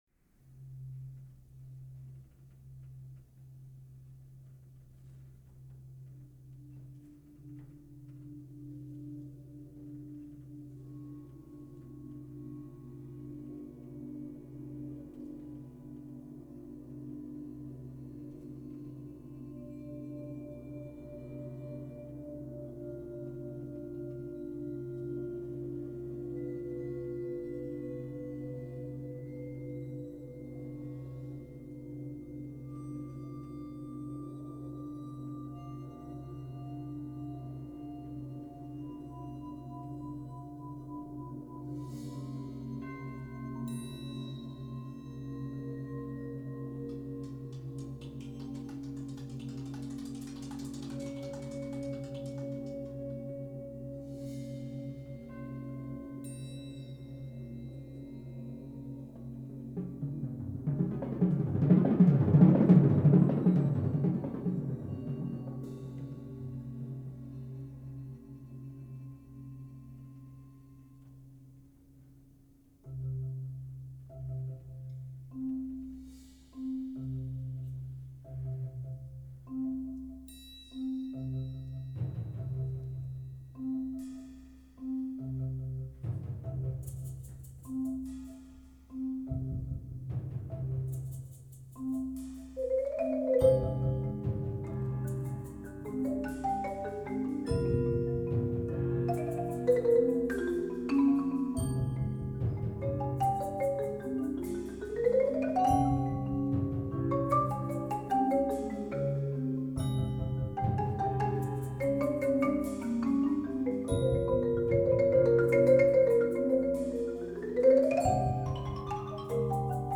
Voicing: 11 Percussion